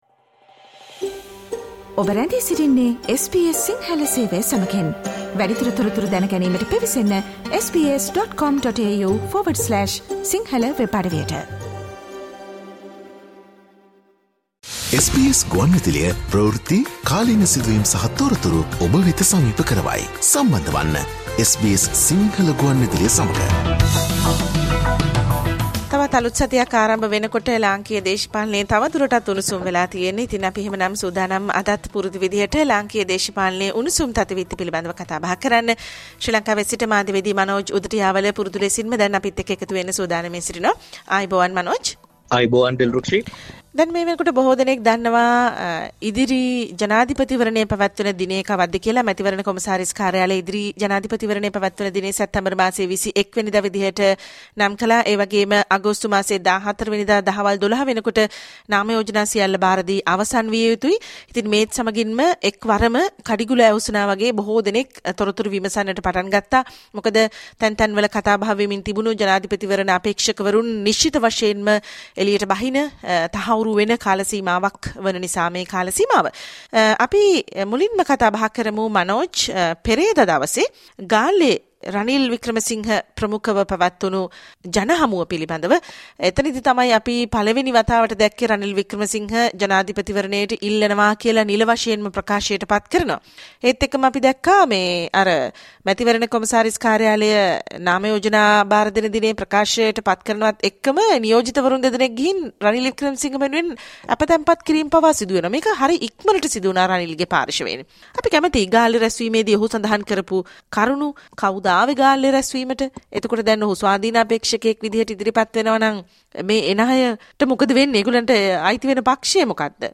Listen to Sri Lanka’s weekly political highlights. Journalist - news, and current affairs